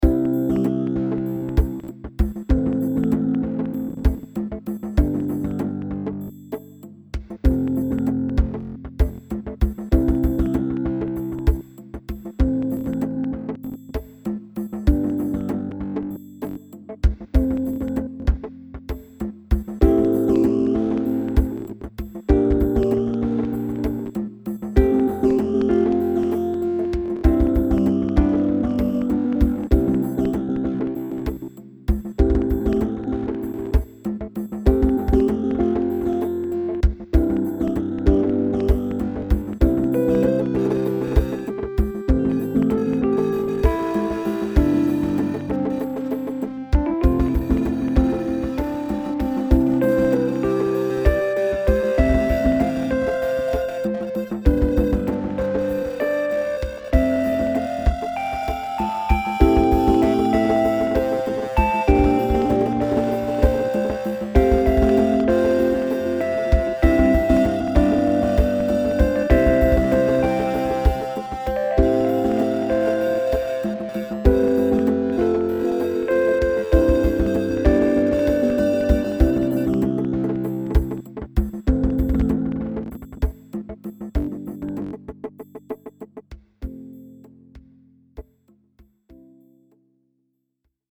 So, I glitched out the drums a bit, and they sounded pretty good now but didn’t match the song at all anymore.
theChange_remix.mp3